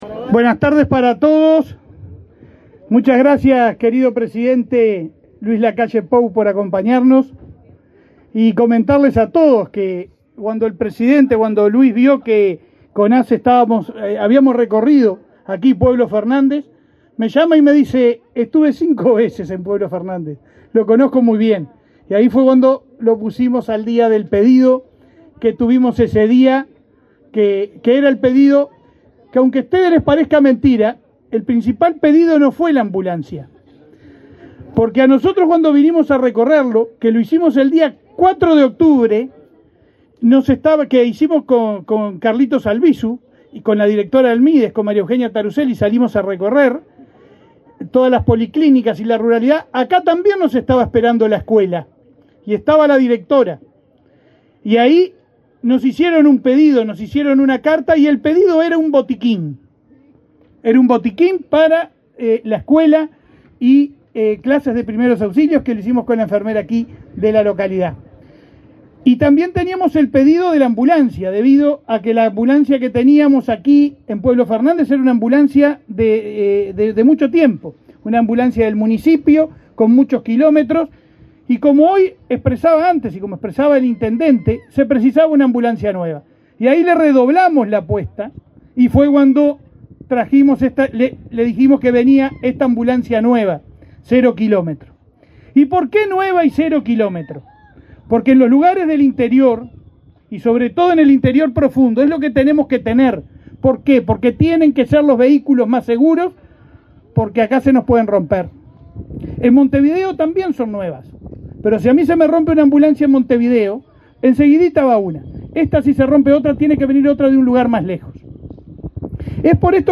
Palabras del presidente de ASSE, Leonardo Cipriani 16/08/2023 Compartir Facebook X Copiar enlace WhatsApp LinkedIn Con la presencia del presidente de la República, Luis Lacalle Pou, la Administración de los Servicios de Salud del Estado (ASSE) entregó, este 16 de agosto, una ambulancia a la policlínica de pueblo Fernández. El presidente de ASSE, Leonardo Cipriani, realizó declaraciones en el evento.